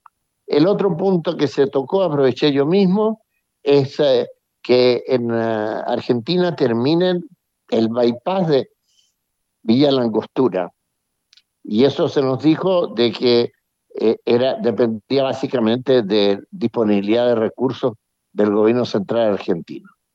Así lo indicó en conversación con Radio Bío Bío, detallando que esta solicitud se efectuó en medio de las reuniones conjuntas de ambos países sobre la conectividad en zonas fronterizas.
embajador-viera-gallo.mp3